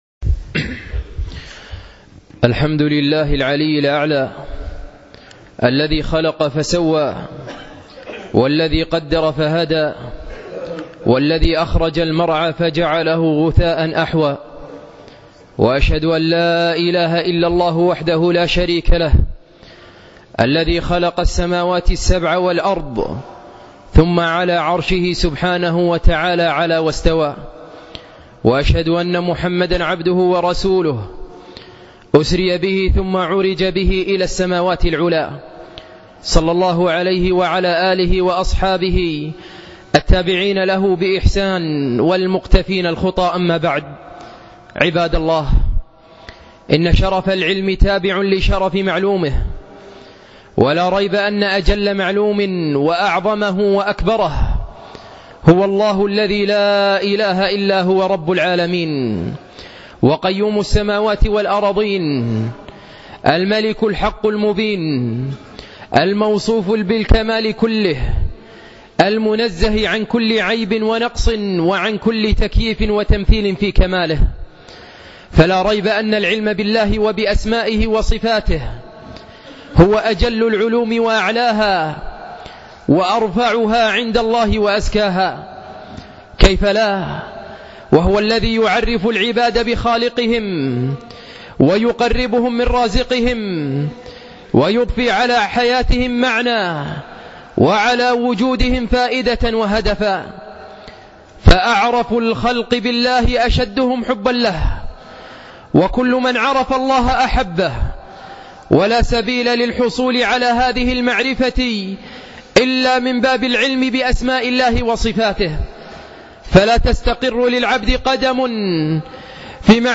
أين الله خطبة